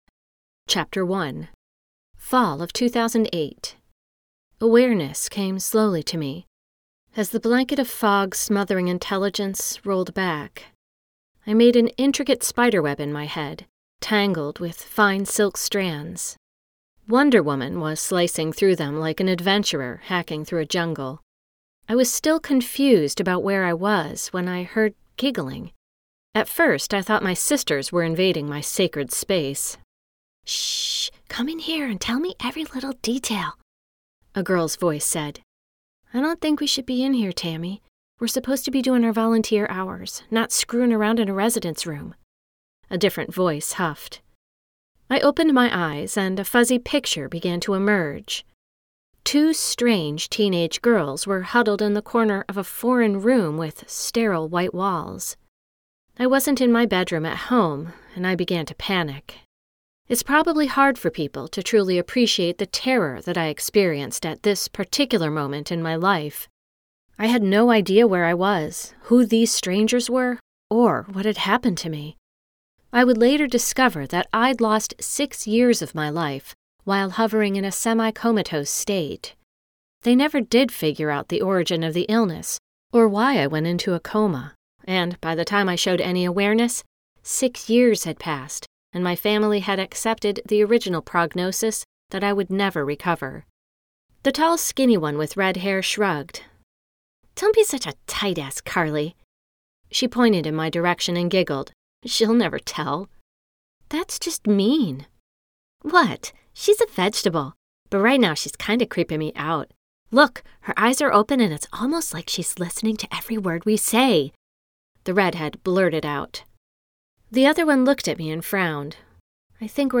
Author Reading